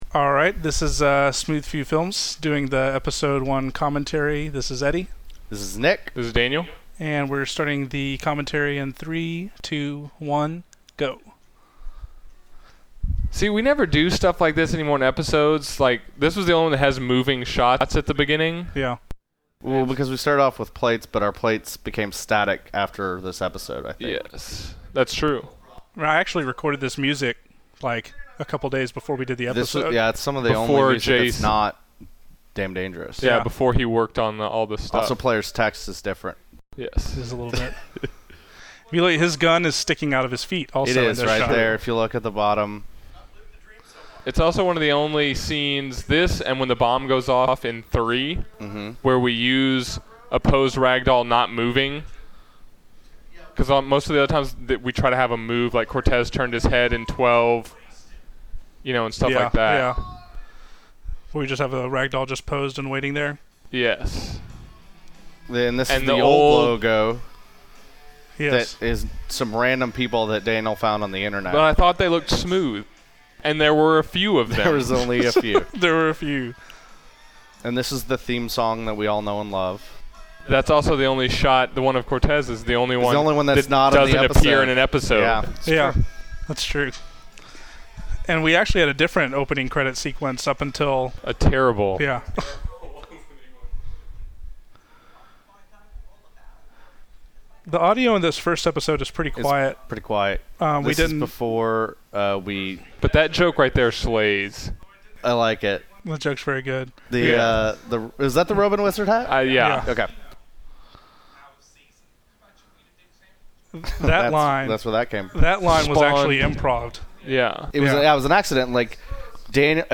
Episode One Commentary
Hopefully you’ll find out a few things that you didn’t know, or just laugh while listening to us goof around during the first episode of The Leet World.
Once the commentary starts, we give you a little countdown so you know when to start the episode on your end (hit play on “go”), that way you’ll be in sync with what we’re saying.
tlw101_commentary.mp3